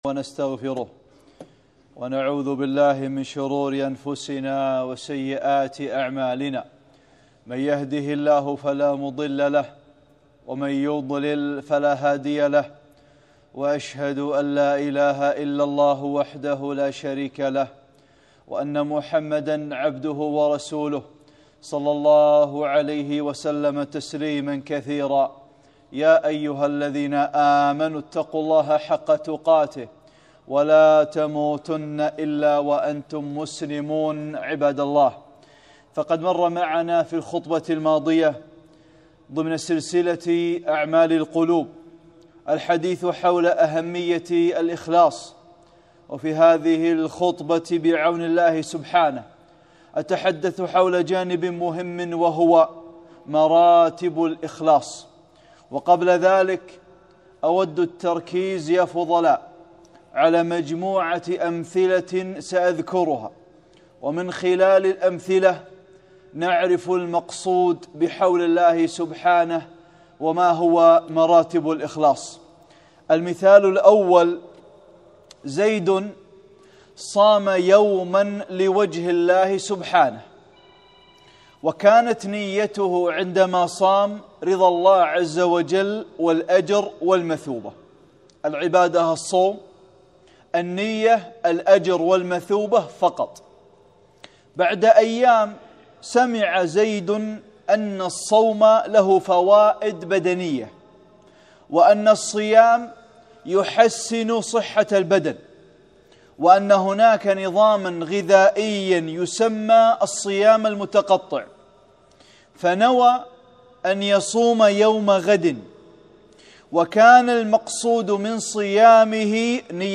(11) خطبة - مراتب الإخلاص